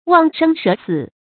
忘生舍死 注音： ㄨㄤˋ ㄕㄥ ㄕㄜˇ ㄙㄧˇ 讀音讀法： 意思解釋： 忘卻性命，不怕犧牲。